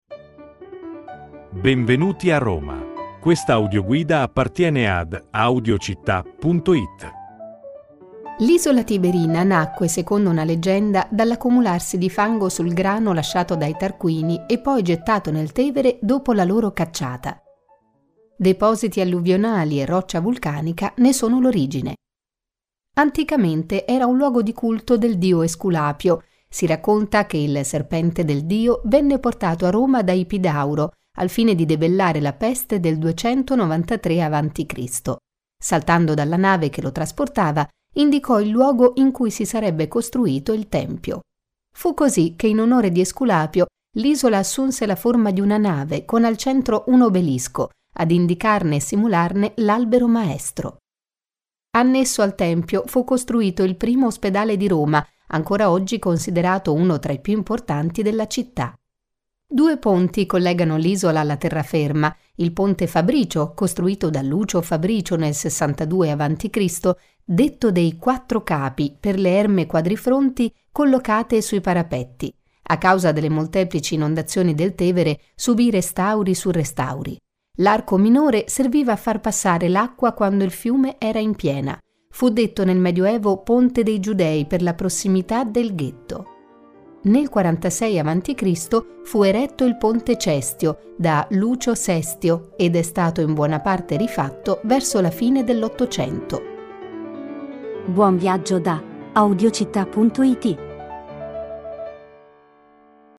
Audioguida Roma – Isola Tiberina